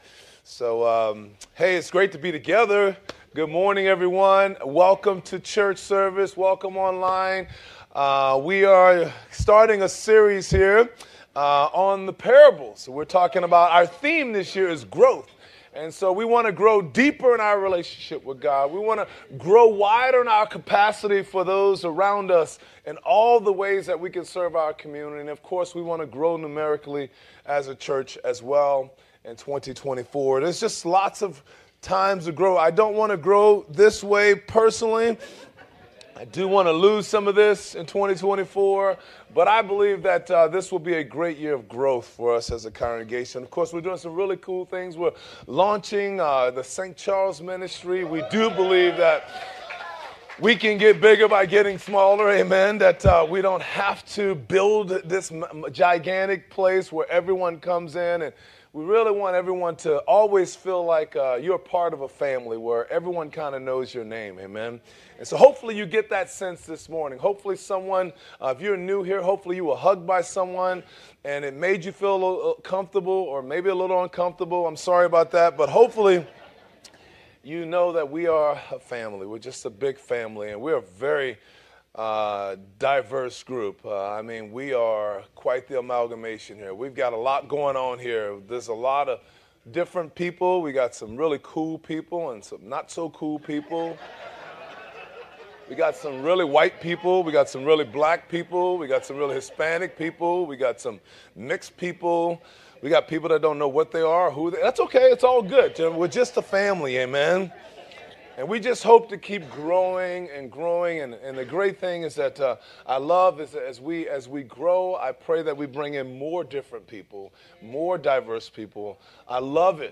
Sermons | Gateway City Church